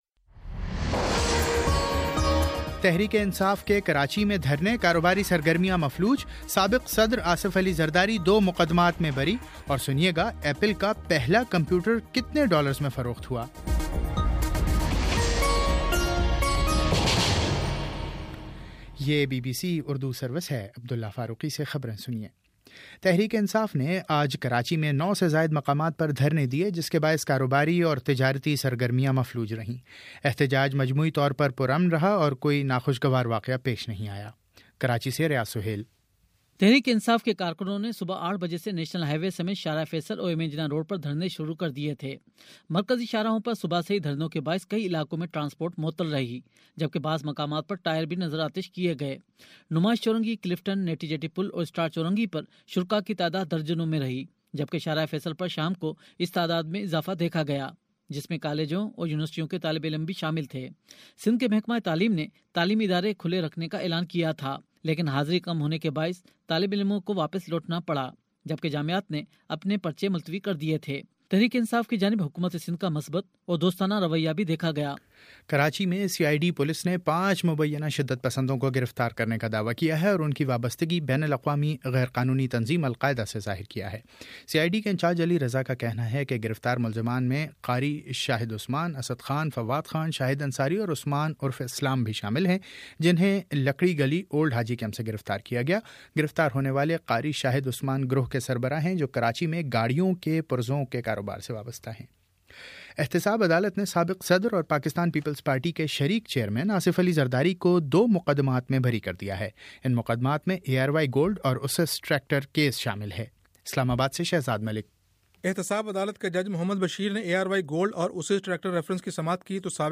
دسمبر 12 : شام چھ بجے کا نیوز بُلیٹن